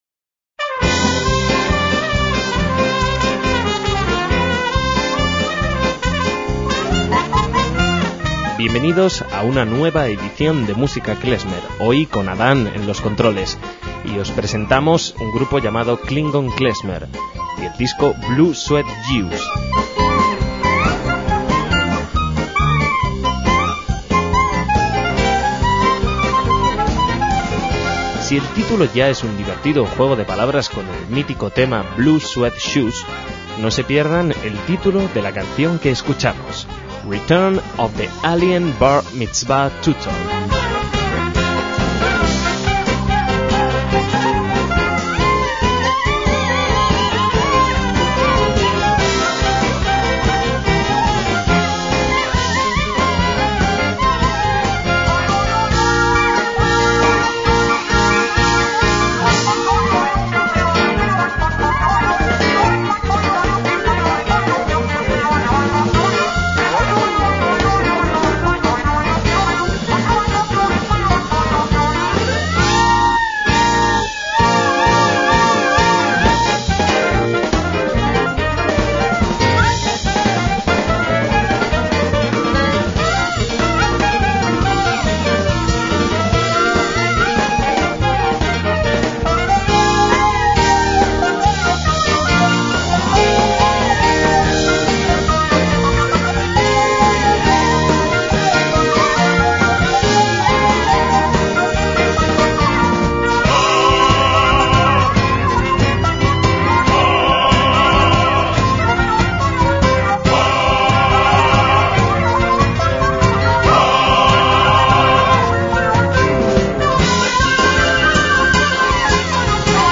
excéntrico violín eléctrico de cinco cuerdas
contrabajo